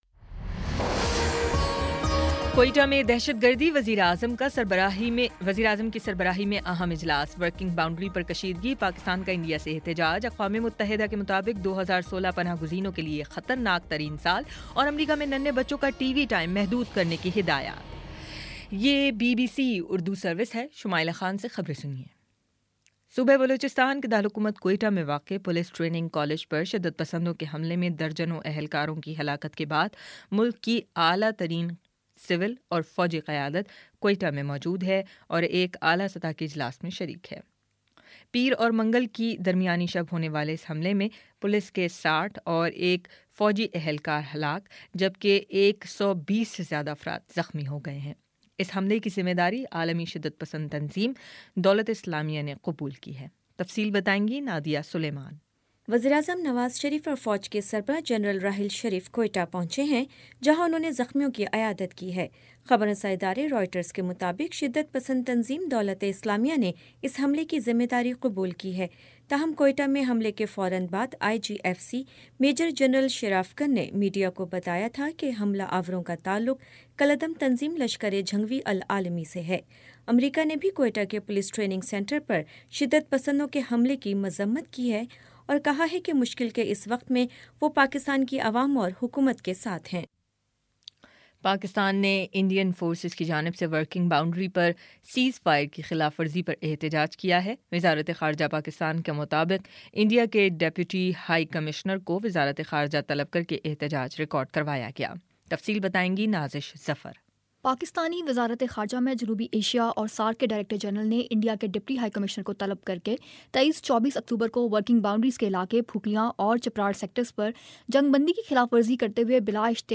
اکتوبر25 : شام سات بجے کا نیوز بُلیٹن